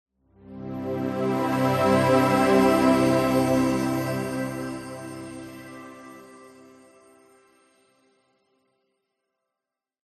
Спокойный мягкий теплый музыкальный идентификатор лого 4